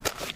MISC Concrete, Foot Scrape 08.wav